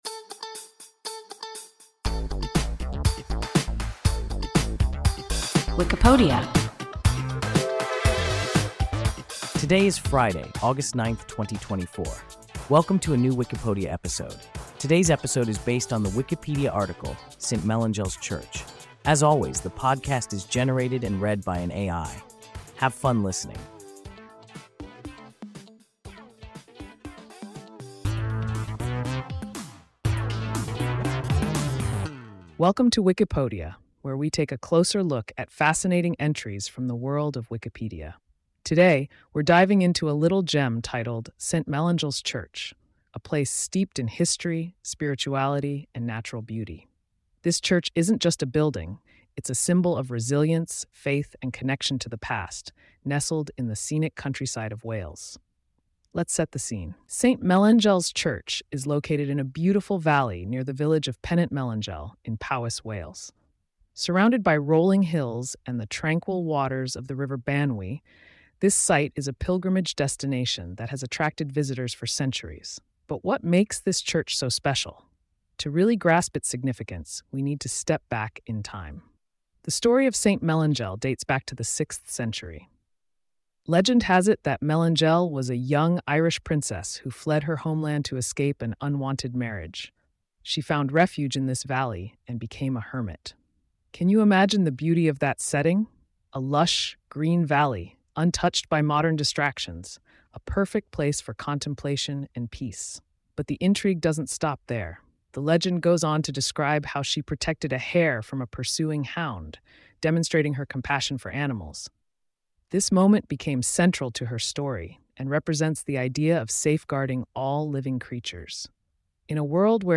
St Melangell’s Church – WIKIPODIA – ein KI Podcast